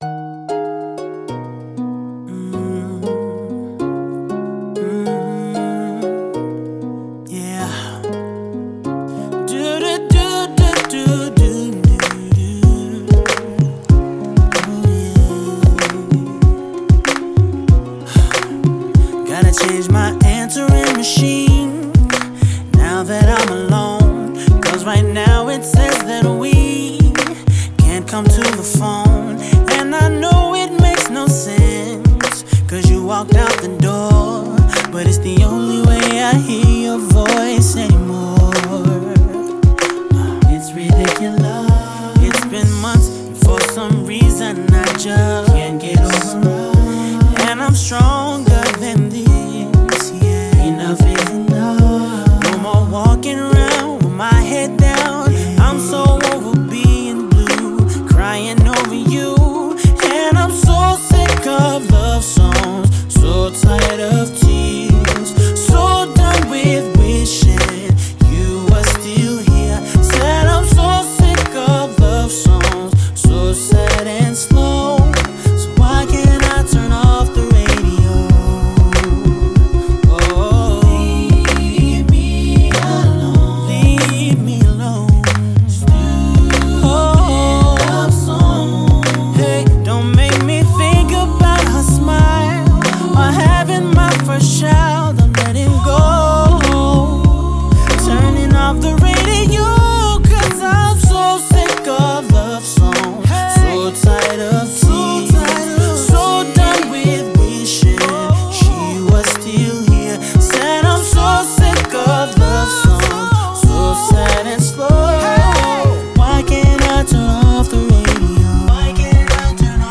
BPM95
MP3 QualityMusic Cut